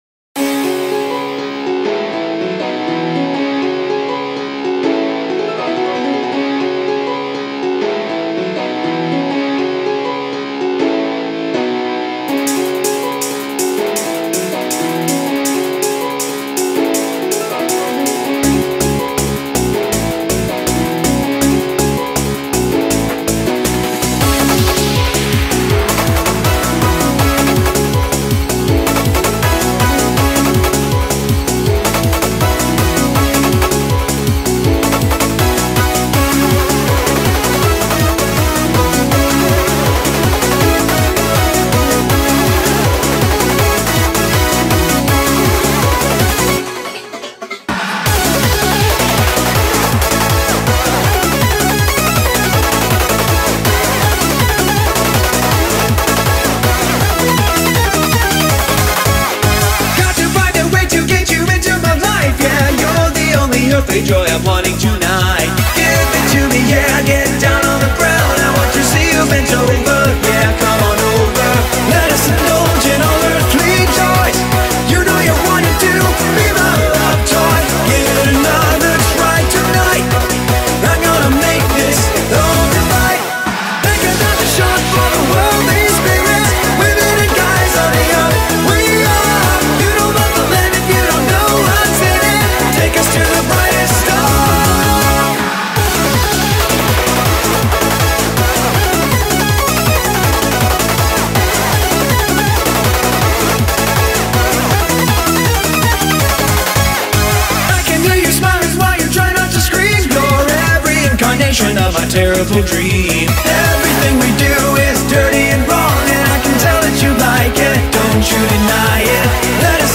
BPM80-161
Audio QualityCut From Video